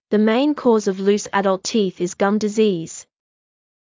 ｻﾞ ﾒｲﾝ ｺｰｽﾞ ｵﾌﾞ ﾙｰｽ ｱﾀﾞﾙﾄ ﾃｨｰｽ ｲｽﾞ ｶﾞﾑ ﾃﾞｨｼﾞｰｽﾞ